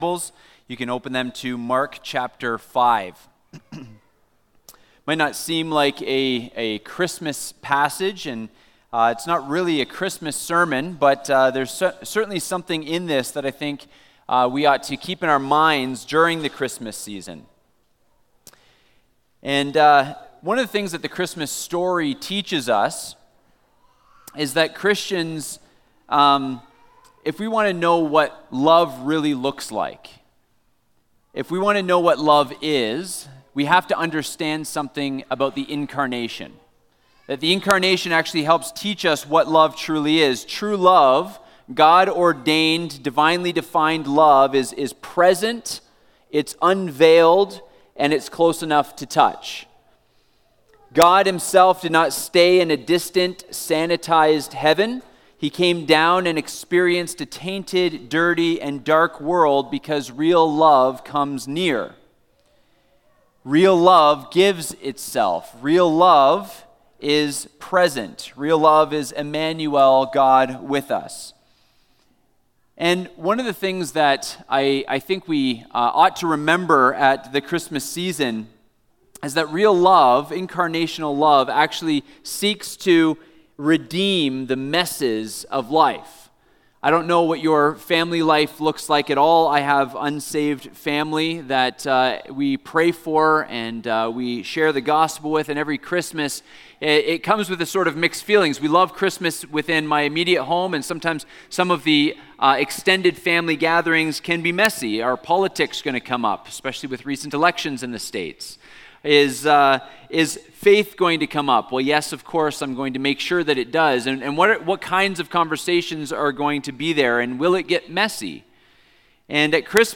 In this sermon we follow the narrative of Jesus ministry when he comes back into Jewish territory and is confronted by two women who need a miracle.